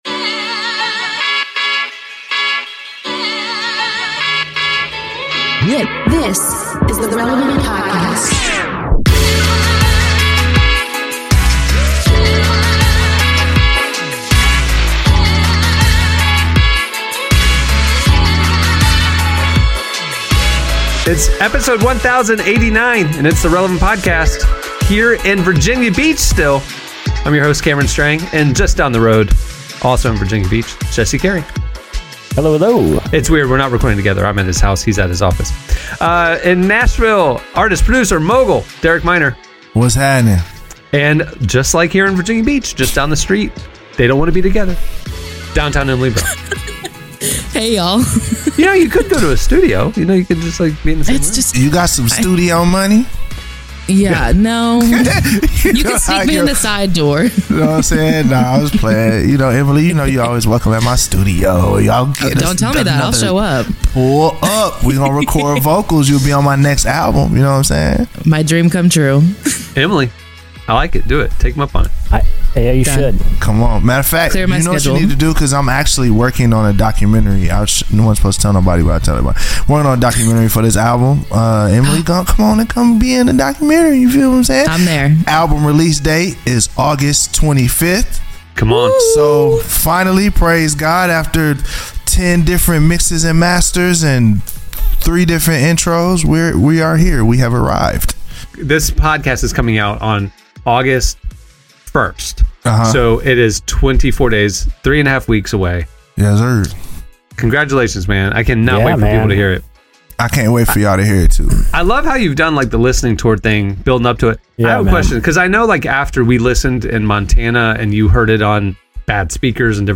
Dr. Russell Moore joins us to discuss how the Church has shifted over the last several years, and how there’s still time to change course.